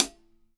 Acoustic Closed Hat 05.wav